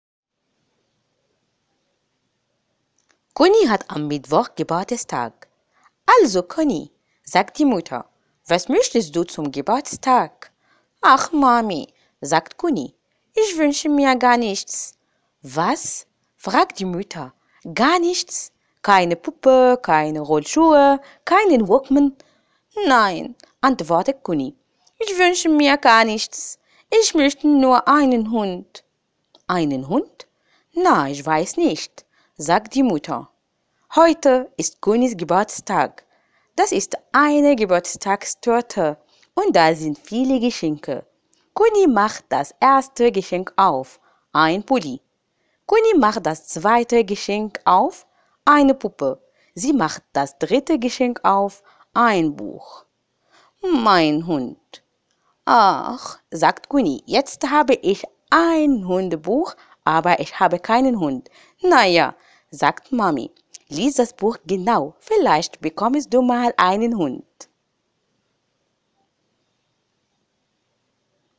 • 5German Female No.2
Character Voice acting